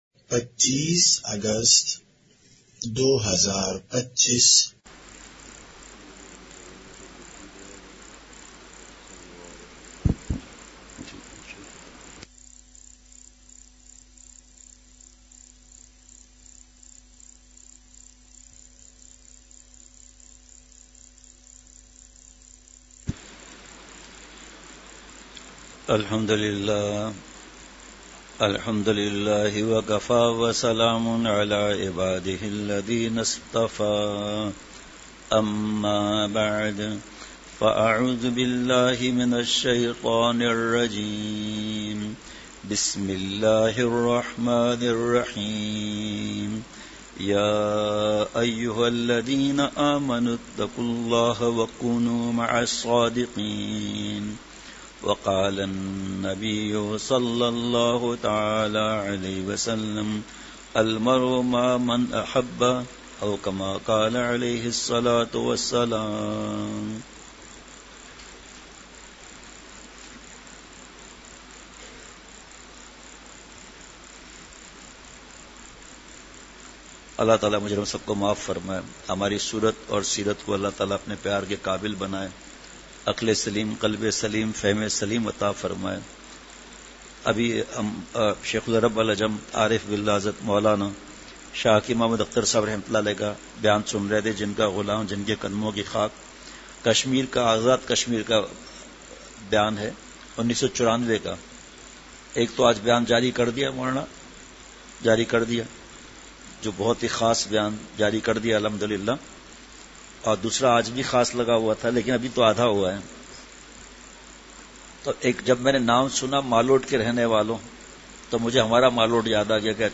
اصلاحی مجلس
مقام:مسجد اختر نزد سندھ بلوچ سوسائٹی گلستانِ جوہر کراچی